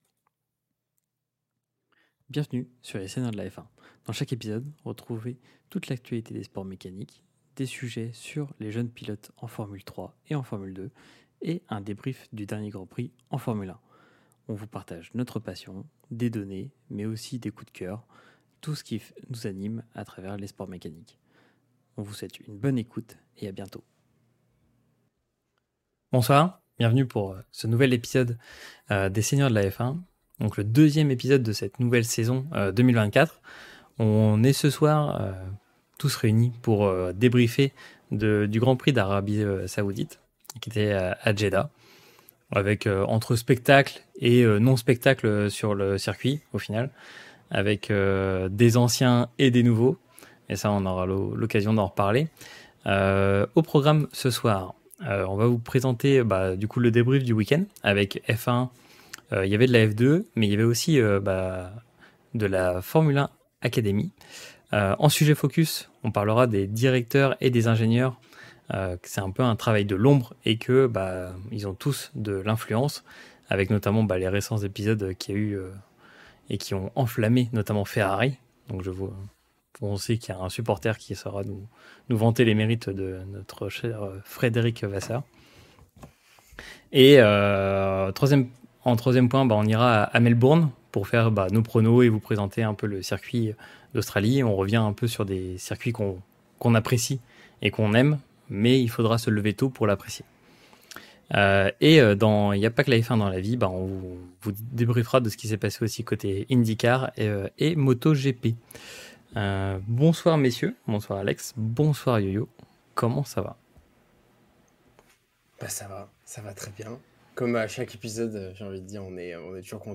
Retrouvez nous en live lors de l'enregistrement sur Twitch